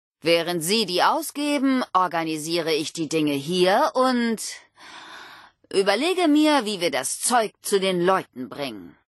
Audiodialoge